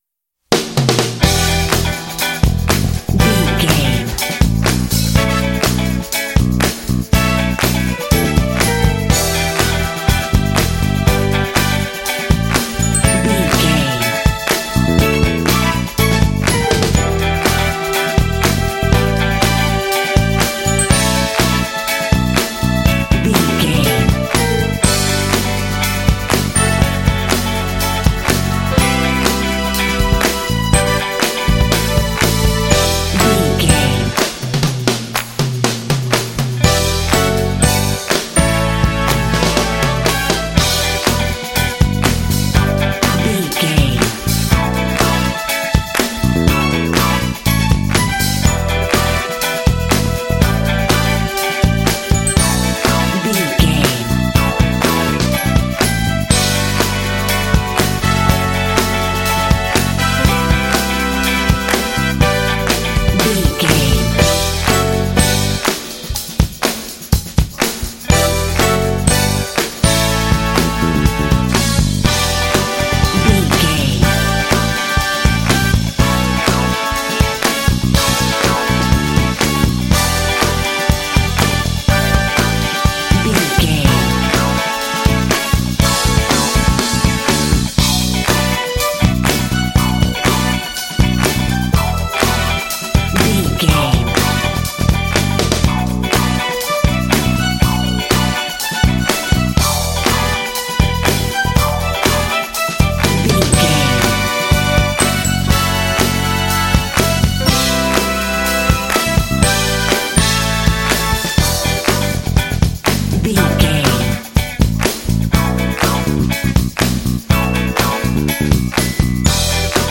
Aeolian/Minor
funky
groovy
driving
energetic
lively
strings
bass guitar
electric guitar
drums
brass